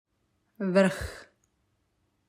5. Vrh (click to hear the pronunciation)
Hint: don’t invest too much strength in the ‘r’.